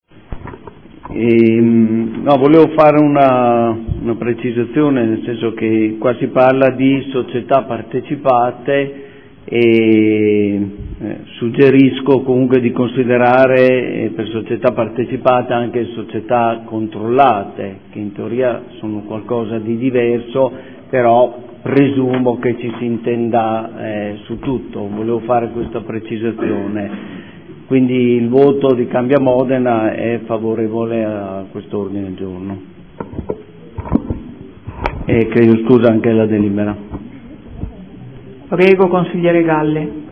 Seduta del 05/02/2015 Dichiarazione di voto. Definizione degli indirizzi per la nomina e la designazione dei rappresentanti nel Comune presso Enti, Aziende, Istituzioni e Società partecipate